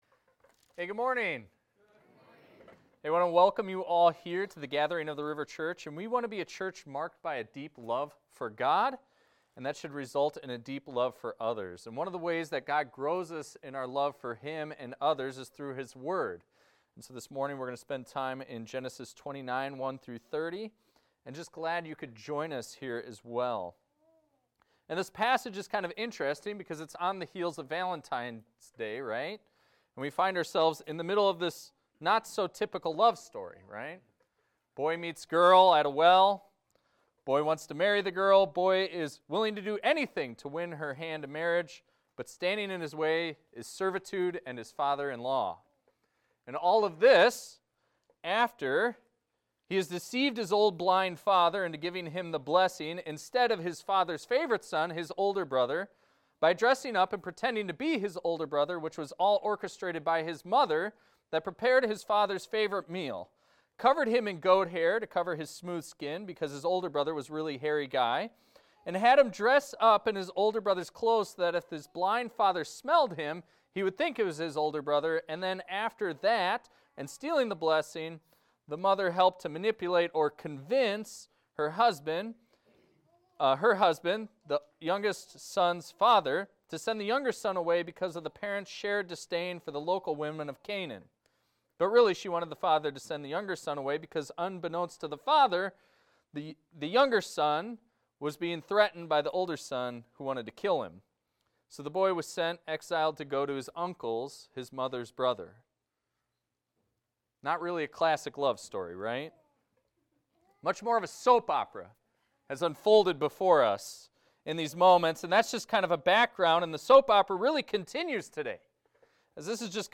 This is a recording of a sermon titled, "Tricking the Trickster."